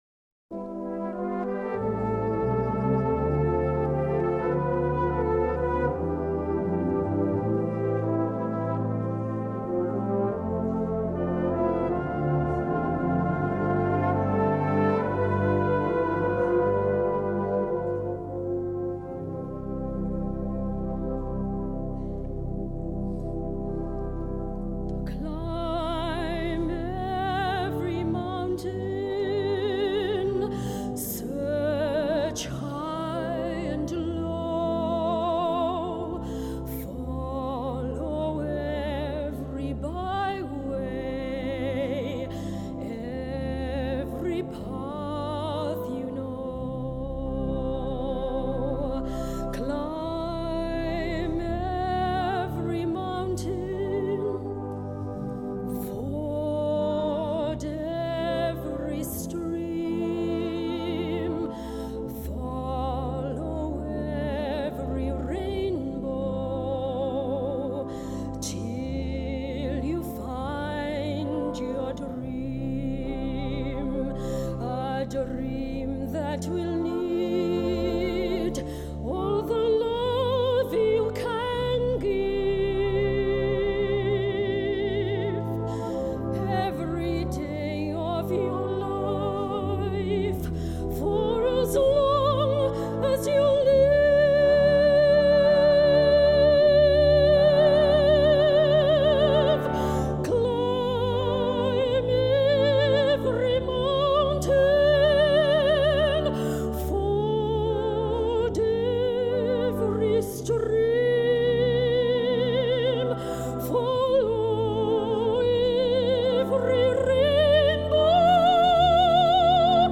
70th -D Day Gala Concert 2014
Liverpool Philharmonic Hall
soprano
Celebration Singers and Northop Silver Band
Concert extract                          D Day Concert 2014